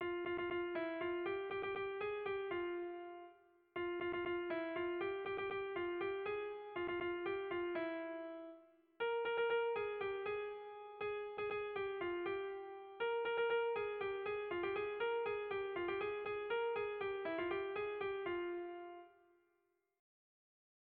Tragikoa
Hamarreko txikia (hg) / Bost puntuko txikia (ip)
A1-A2-B-C